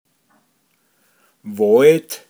pinzgauer mundart
Wåid Wald